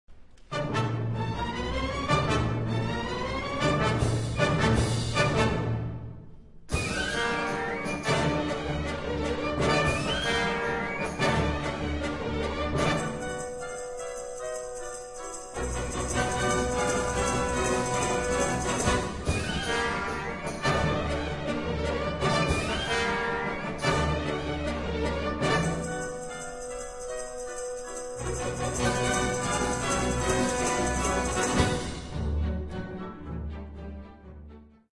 Caucasian Dance